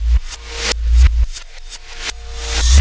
Black Hole Beat 17.wav